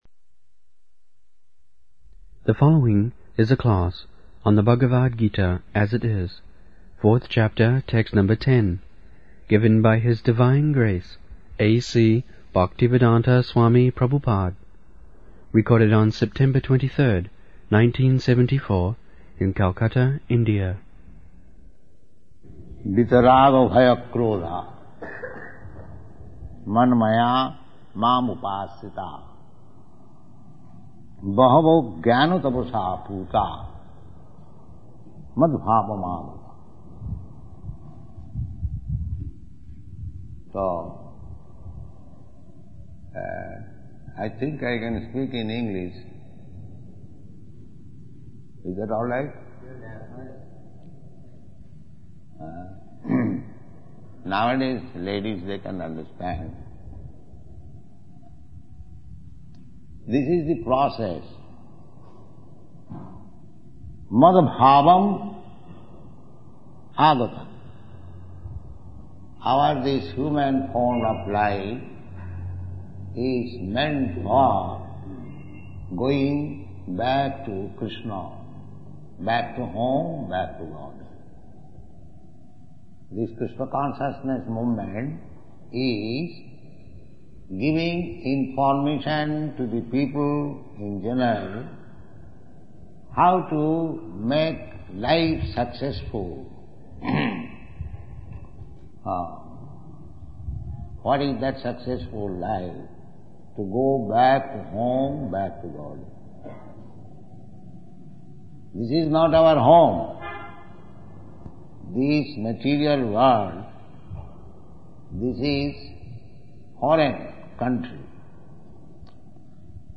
74/09/23 Calcutta, Bhagavad-gita 4.10 Listen